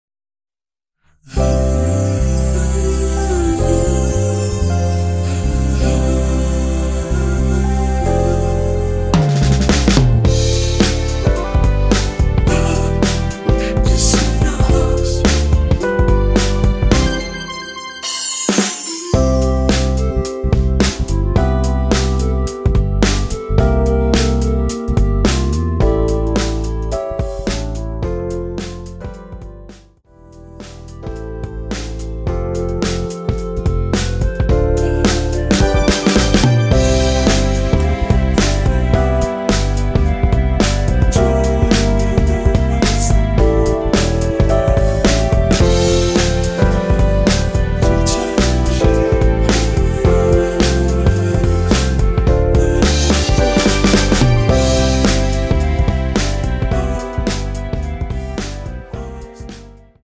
원키에서(-5)내린 멜로디와 코러스 포함된 MR입니다.(미리듣기 확인)
앞부분30초, 뒷부분30초씩 편집해서 올려 드리고 있습니다.
중간에 음이 끈어지고 다시 나오는 이유는